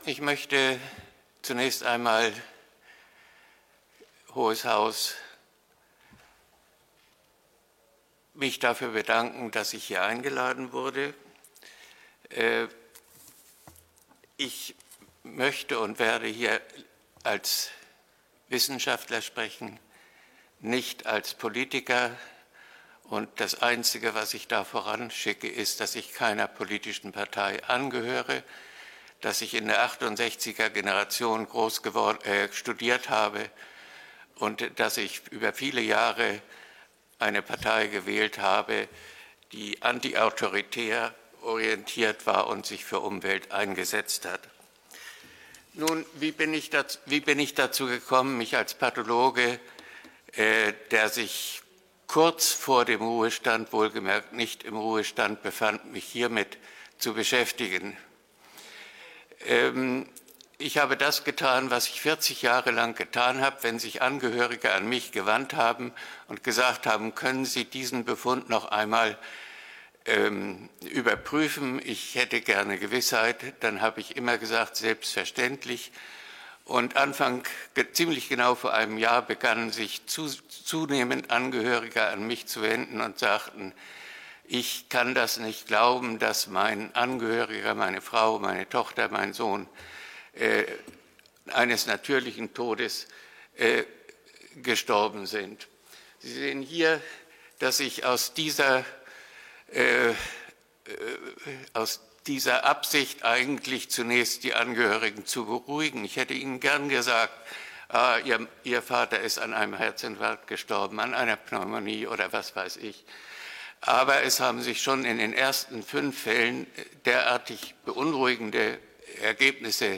Fachvortrag
vor dem Sächsischen Landtag am 11.03.2022